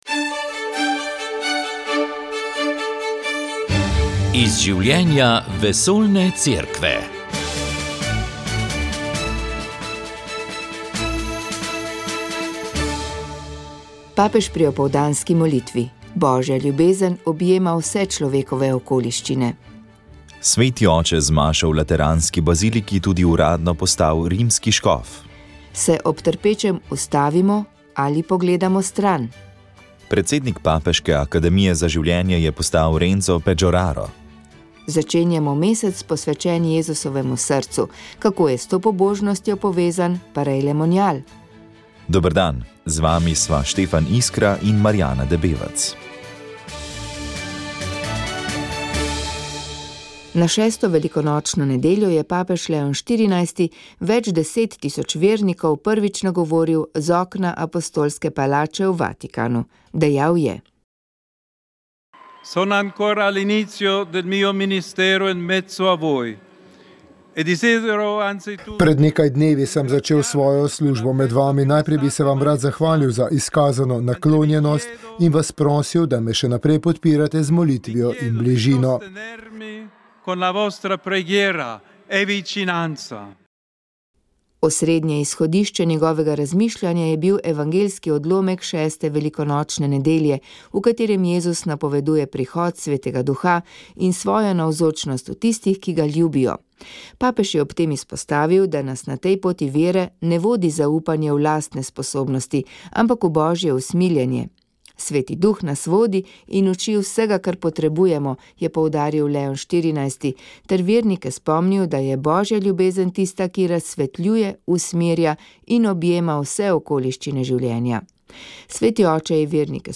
Z mikrofonom smo obiskali udeleženke tečaja ročnih del in bili navdušeni nad njihovo ustvarjalnostjo. S člani študijskega krožka pri Zvezi društev slepih in slabovidnih Slovenije pa smo se pogovarjali o pomenu dostopnosti do namiznih iger in postopkih njihovega prilagajanja.